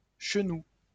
Chenou (French pronunciation: [ʃənu]